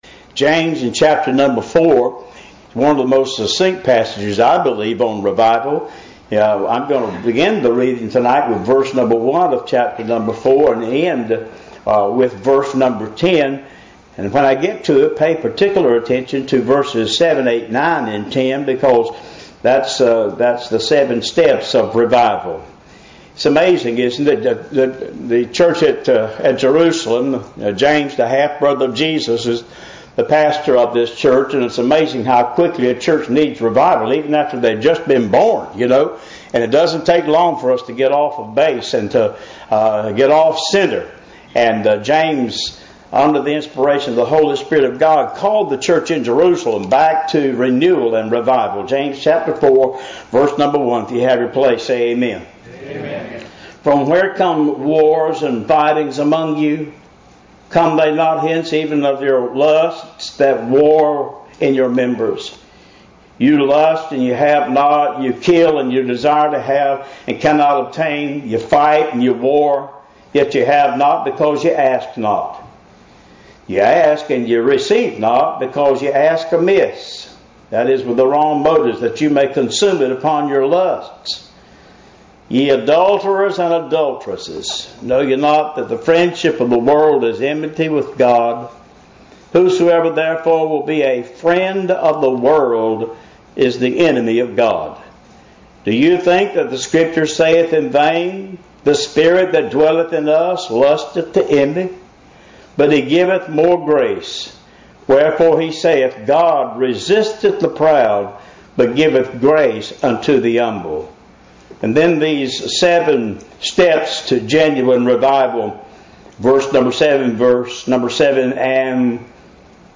Revival – Wednesday Evening